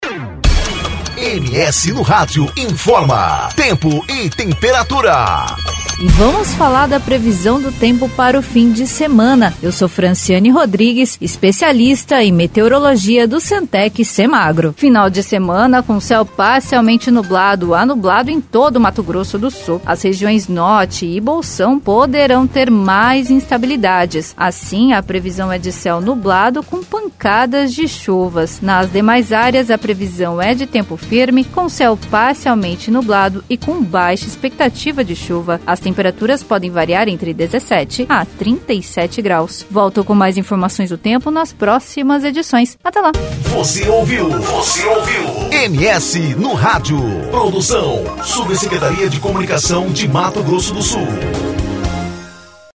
Previsão do Tempo